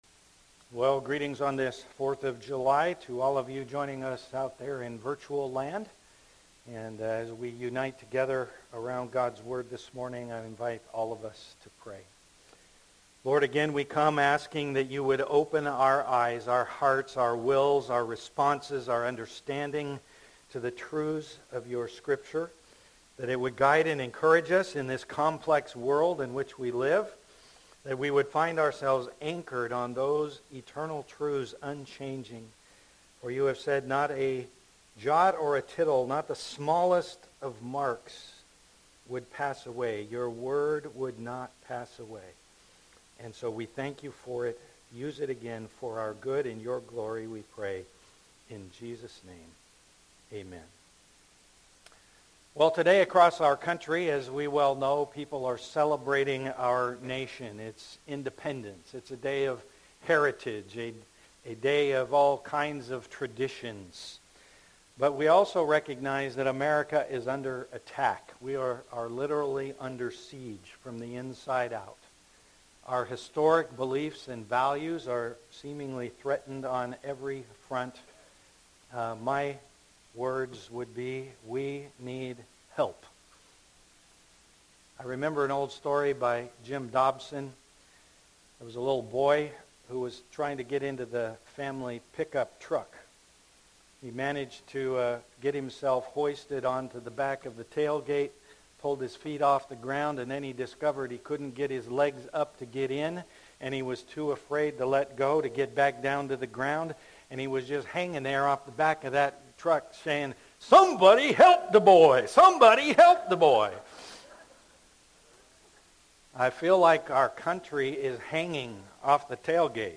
2021 Rx for America Preacher